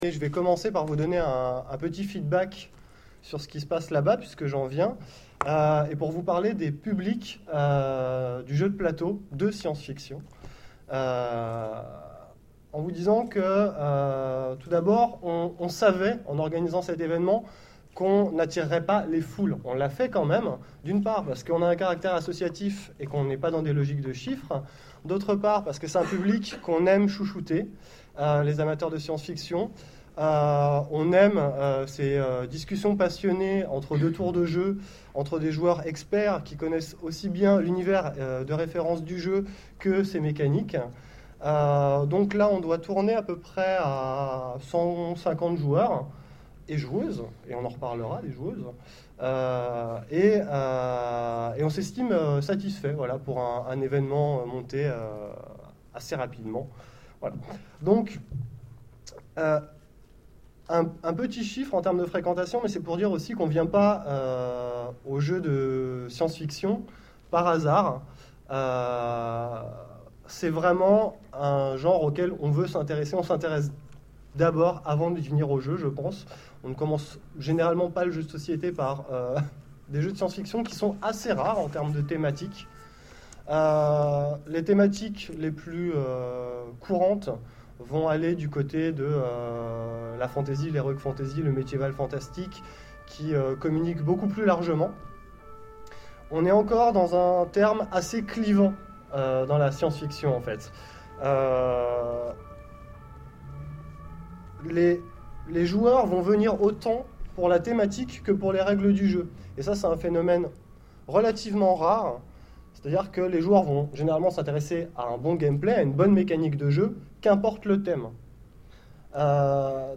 9ème Rencontres de l'Imaginaire de Sèvres : Conférence Créer un jeu de SF aujourd’hui